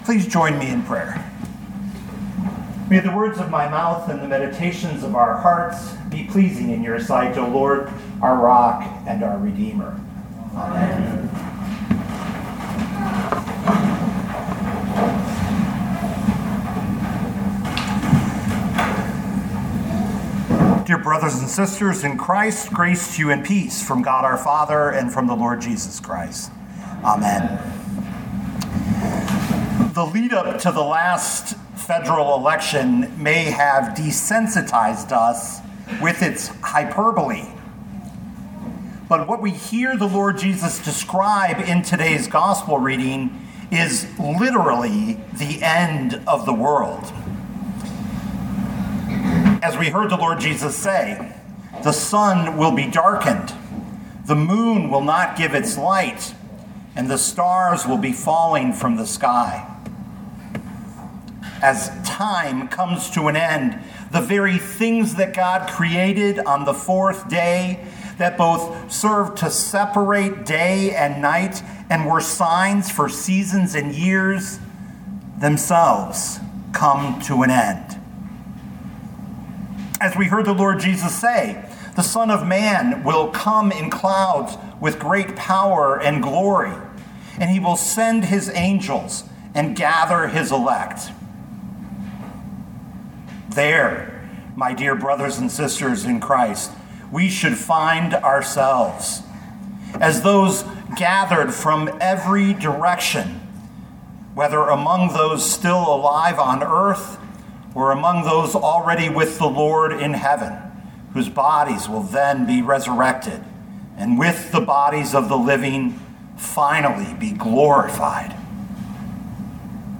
2024 Mark 13:24-37 Listen to the sermon with the player below, or, download the audio.